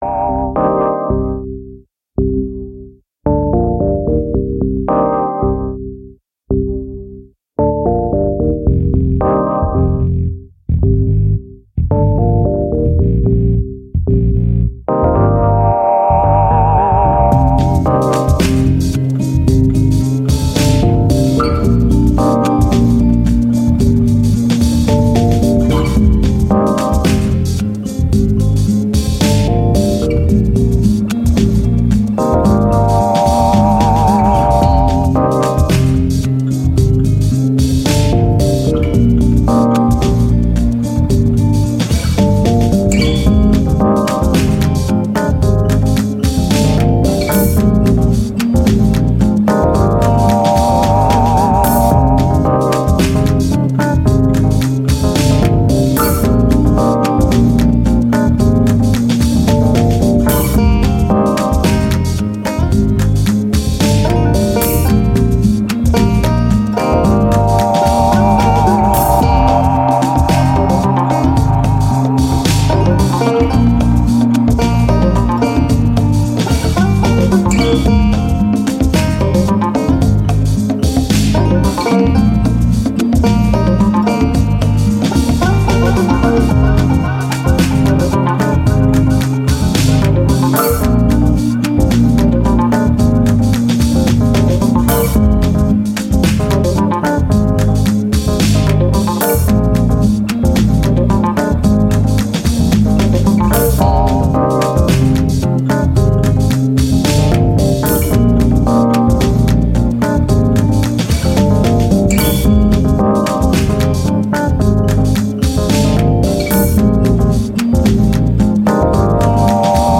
Ambient, Downtempo, Chill, Thoughtful